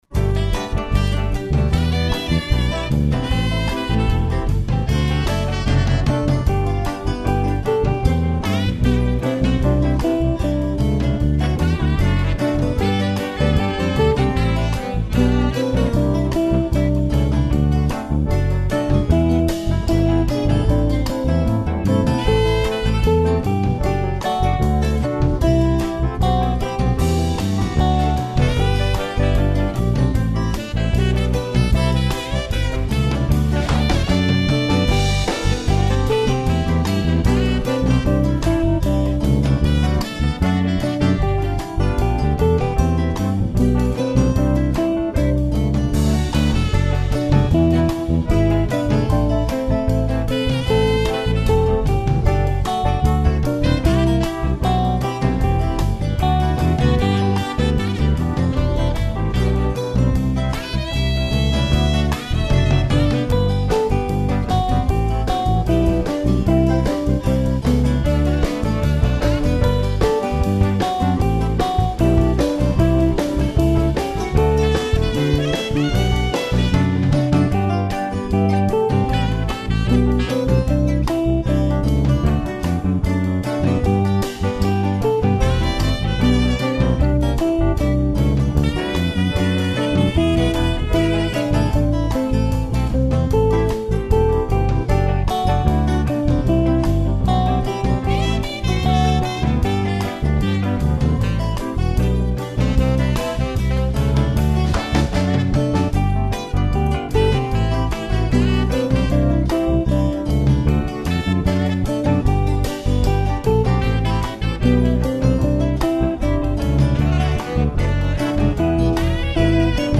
My backing is a little busy but it is spirited as required: